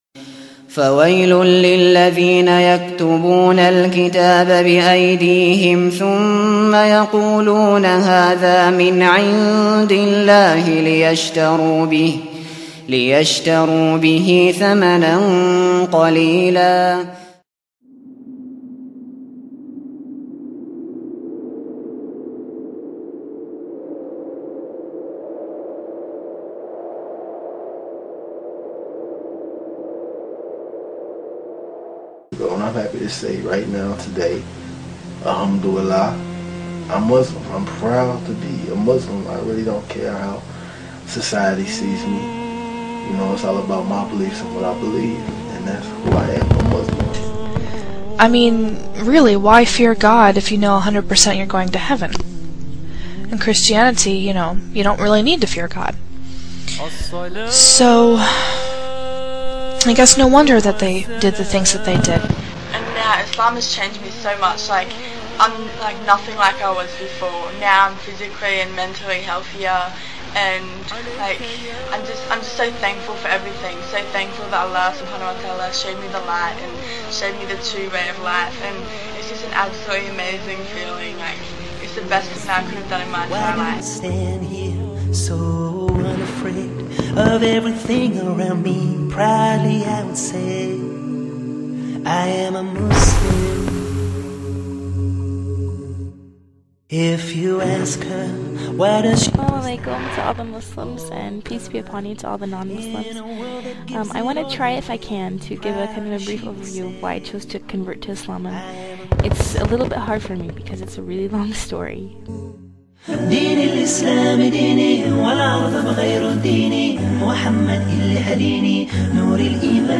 Voices of New Muslims
— A new Muslim sharing their journey